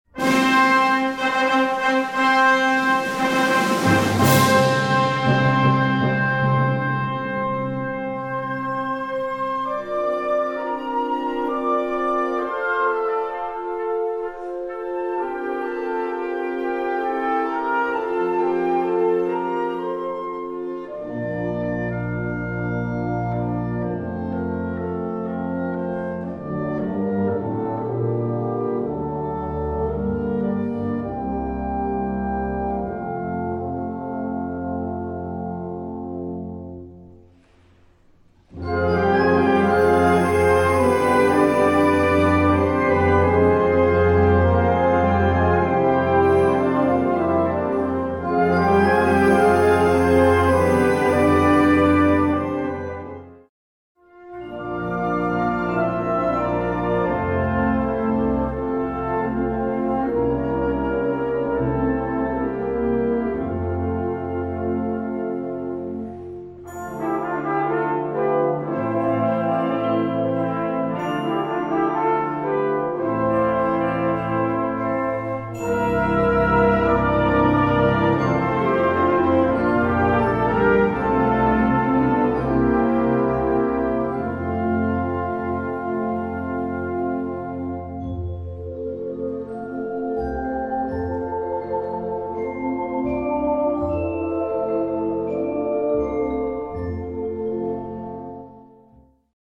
Gattung: Operette
Besetzung: Blasorchester